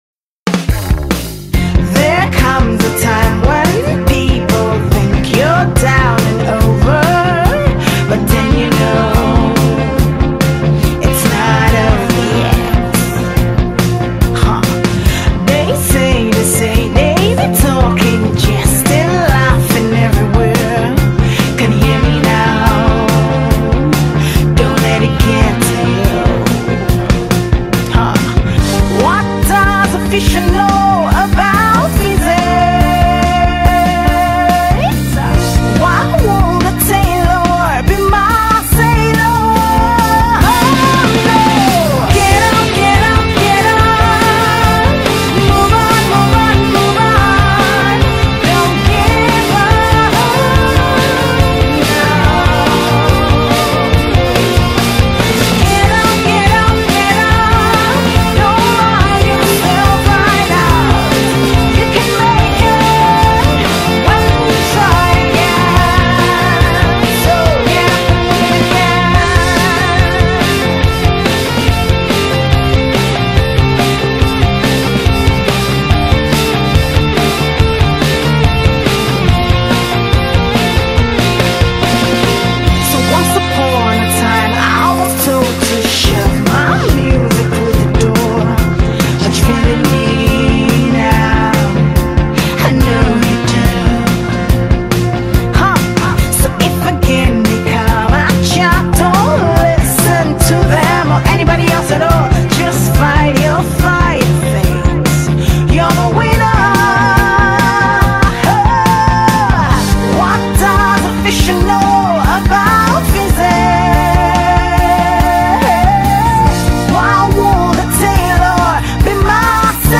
Genre:Gospel